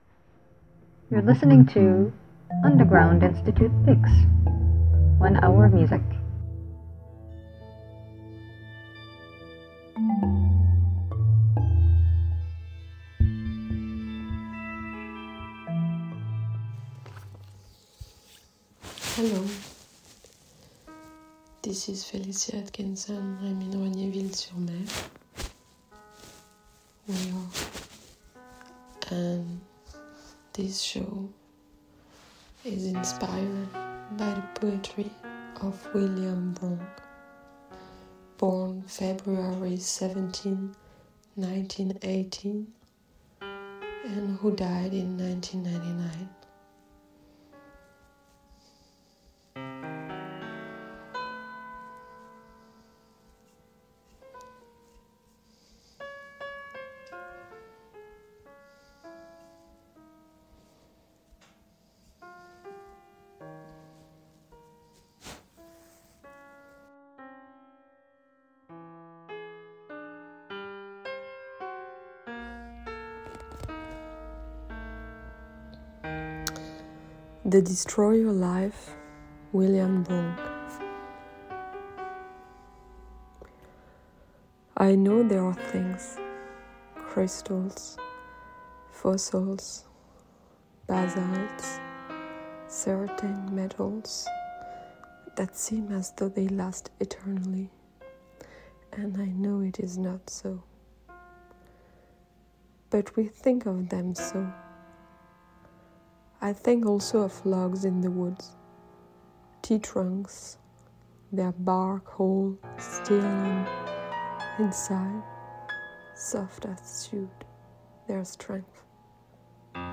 Avant-Garde Poetry Spoken Word
This episode is curated by sound artist and composer